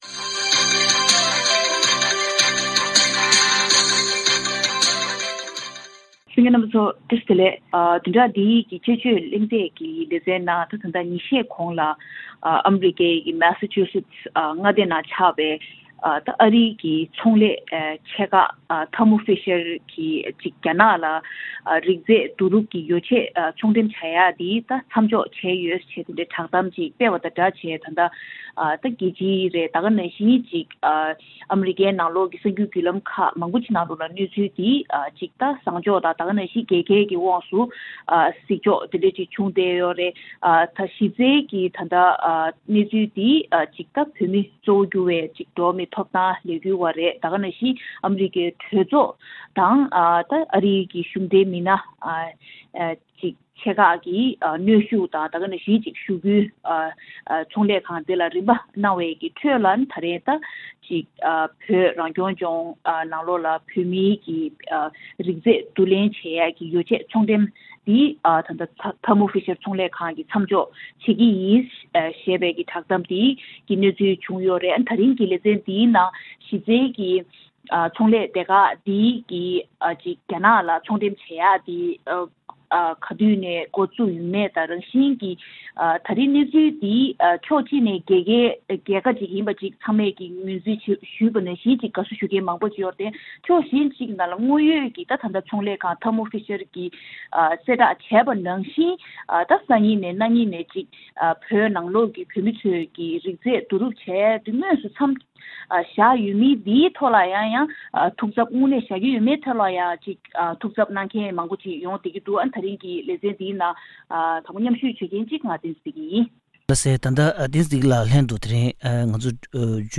དཔྱད་གཞིའི་གླེང་མོལ